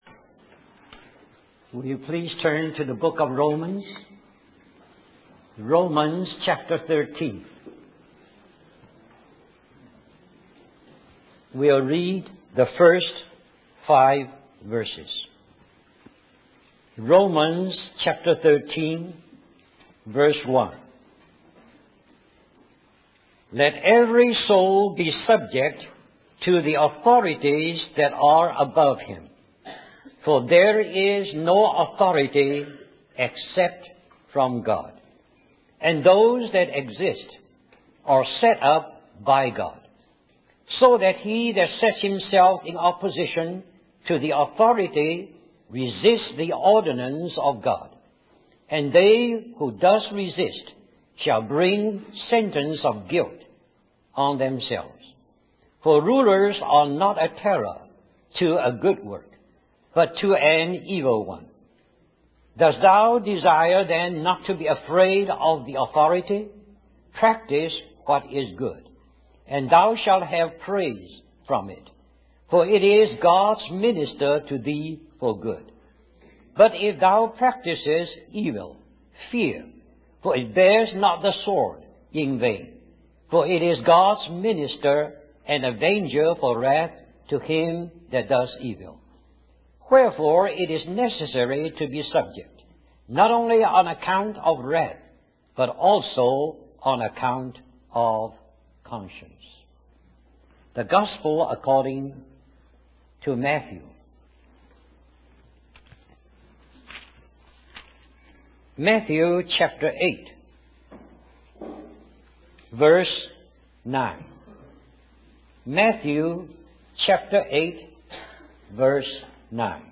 In this sermon, the speaker discusses the challenges of being in authority and being under authority. They emphasize that being in authority is not easy and can be even more difficult than being under authority.